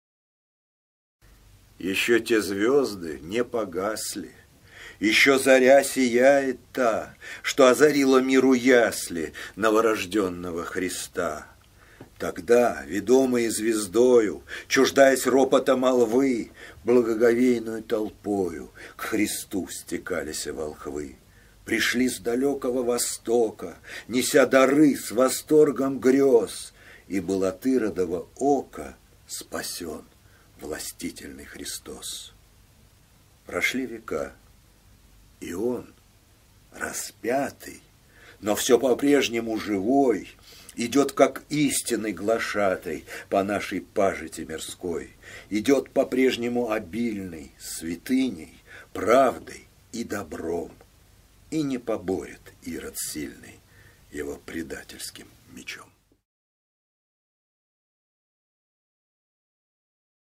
1. «Читает Игорь Костолевский – Константин Фофанов. “Ещё те звезды не погасли…”Проект “Живая поэзия”» /
chitaet-igor-kostolevskij-konstantin-fofanov-eshhyo-te-zvezdy-ne-pogasli-proekt-zhivaya-poeziya